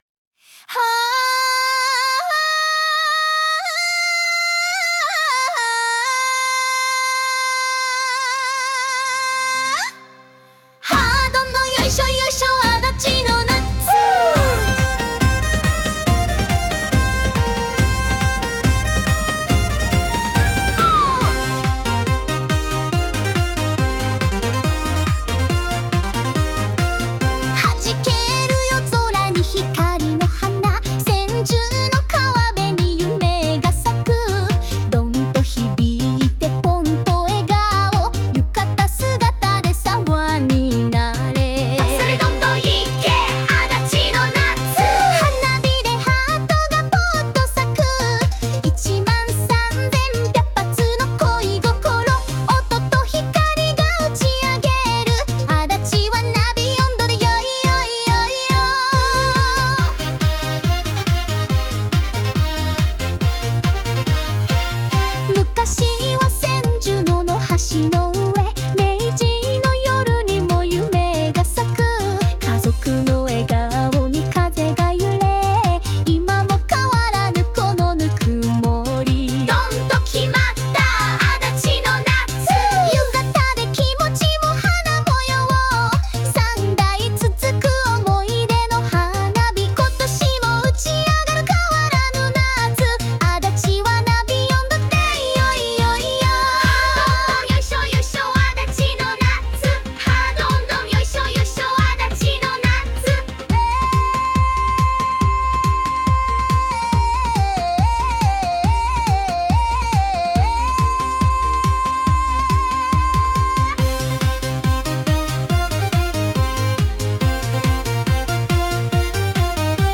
作曲：最新AI